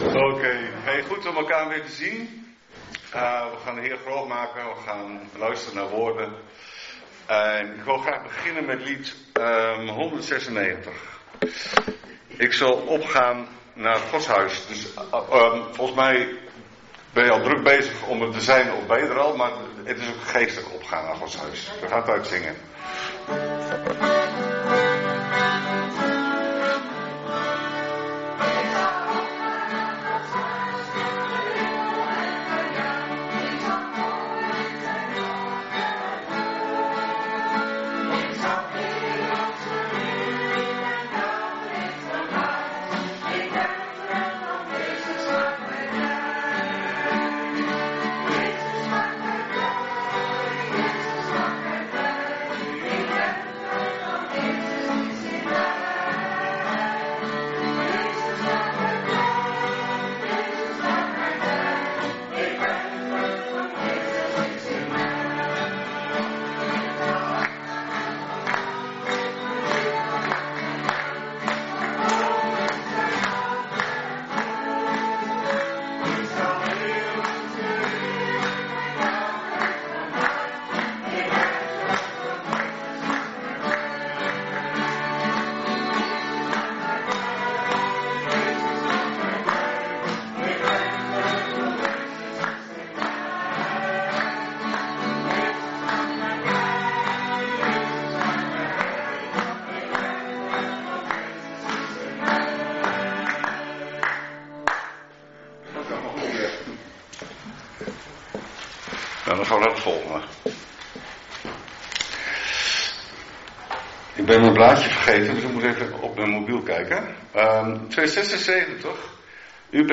9 november 2025 dienst - Volle Evangelie Gemeente Enschede
Preek